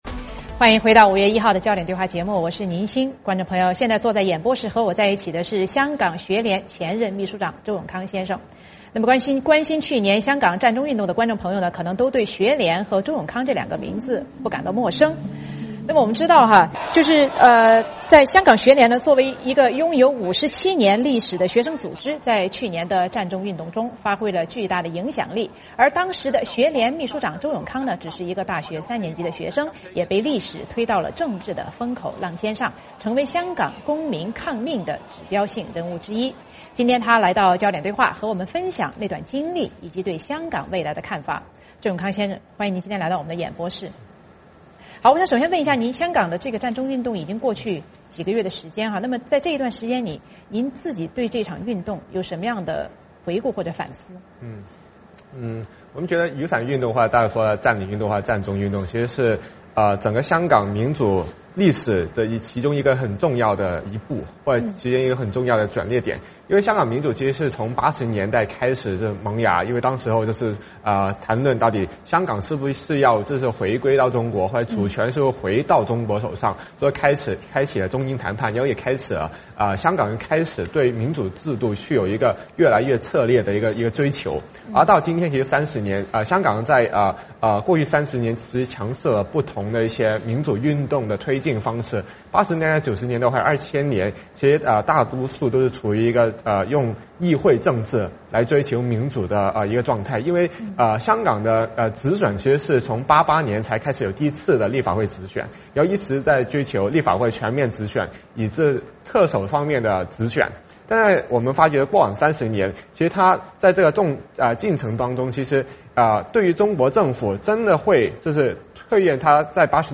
焦点对话：专访香港占中学生领袖周永康
观众朋友，现在坐在演播室和我在一起的是香港学联前任秘书长周永康先生。